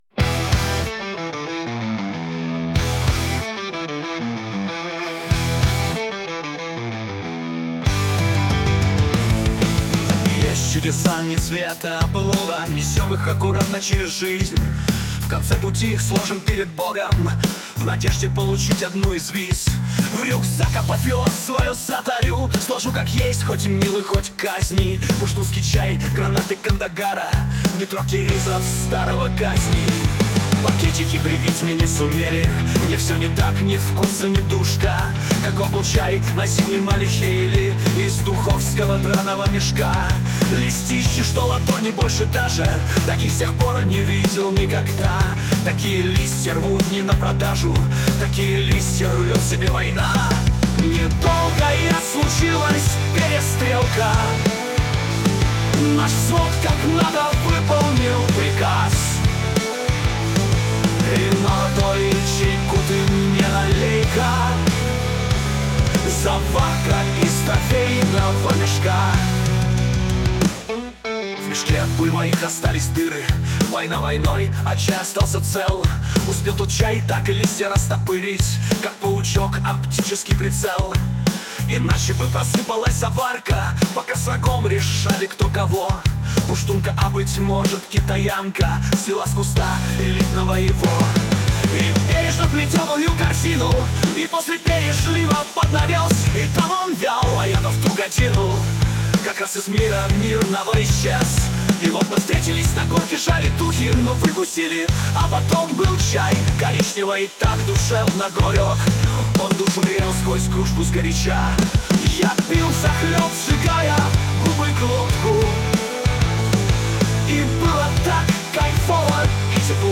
pushtunskijchaj.mp3 (4601k) Попытка песни ИИ